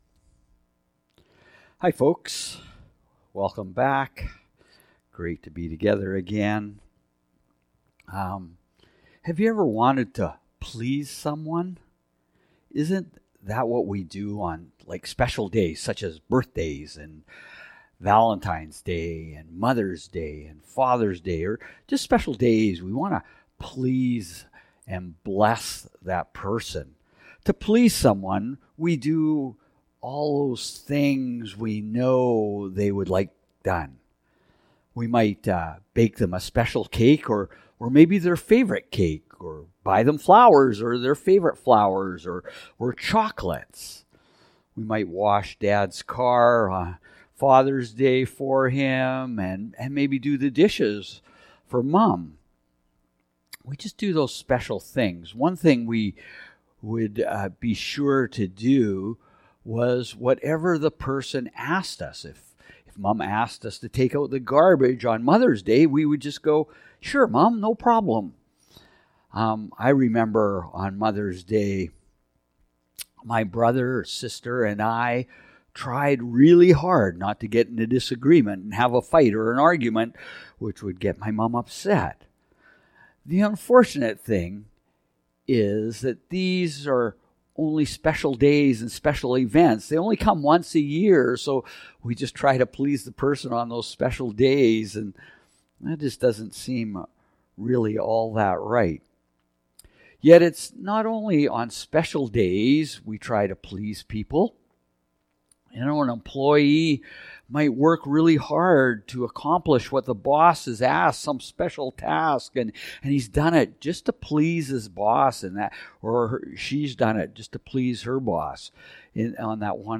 Sermons | Cross Roads Pentecostal Assembly